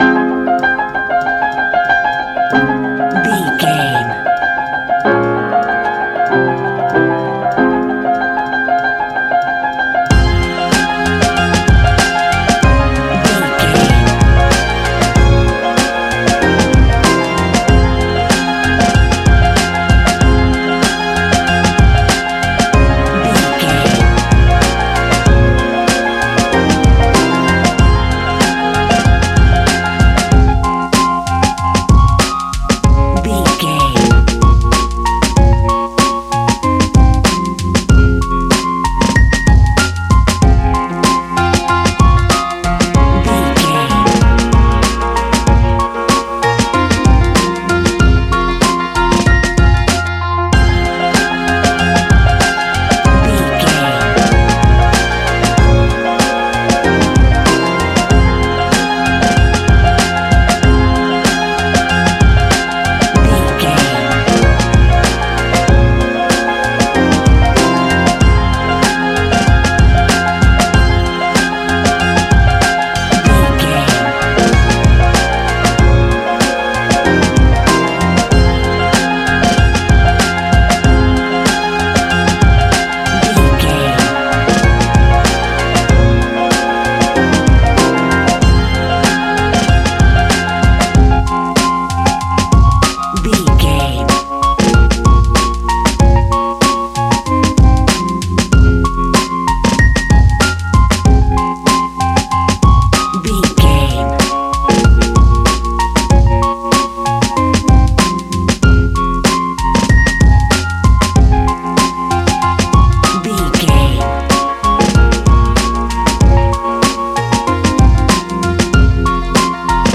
Ionian/Major
A♯
chilled
laid back
Lounge
sparse
new age
chilled electronica
ambient
atmospheric